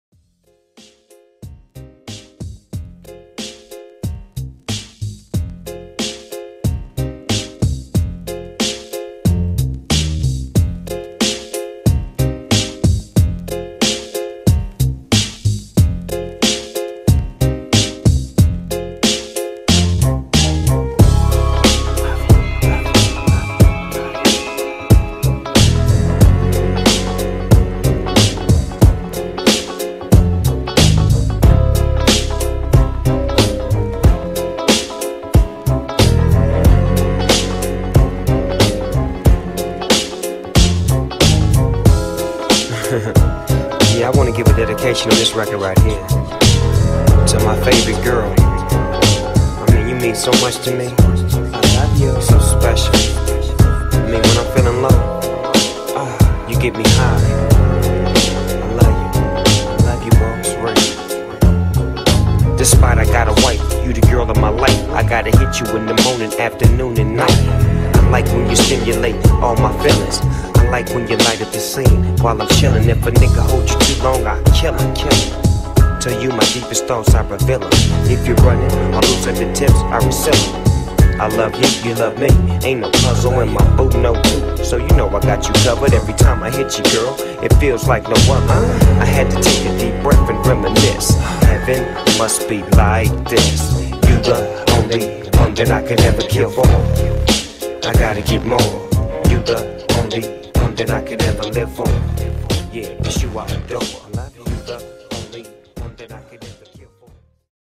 2020 HipHop VIP